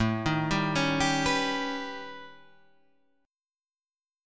A 9th Flat 5th